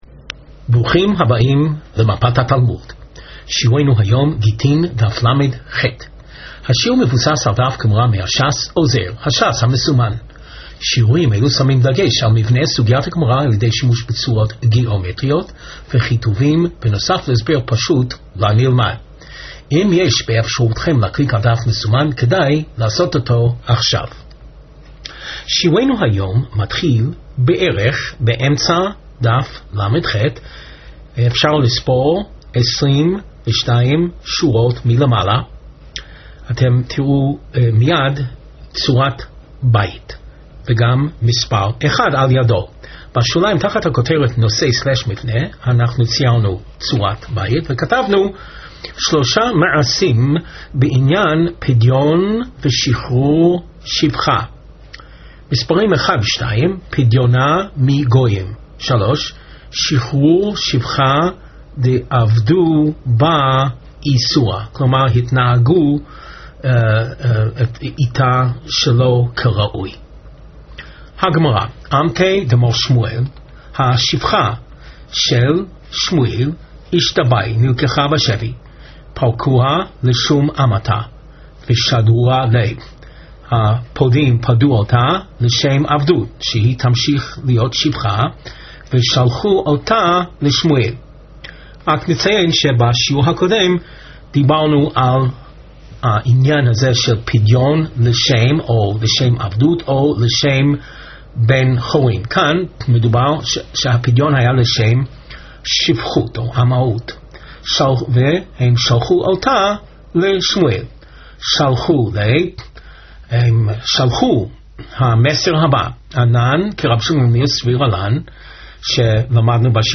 Please click on the above video to hear the Rav give the shiur.